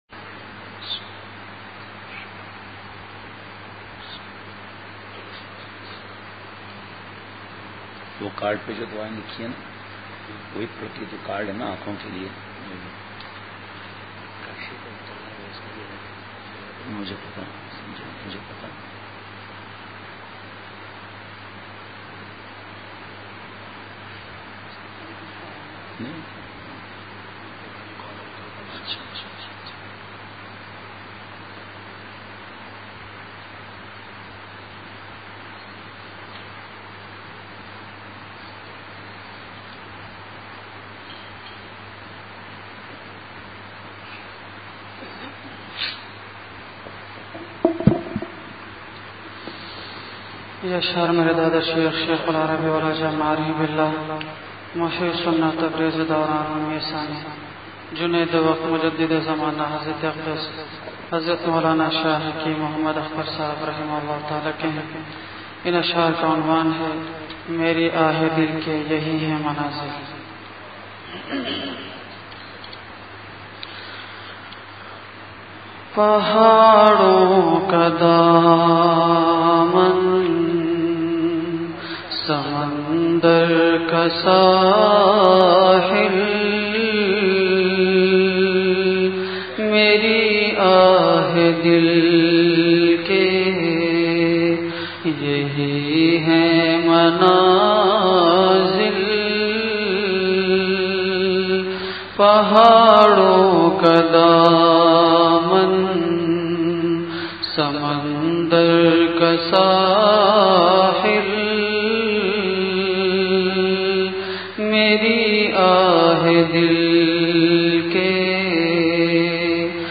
Safar Sa After Magrib 11 Dec 2018 Masjid Darul Uloom Azaadville May Dard Bahra Bayan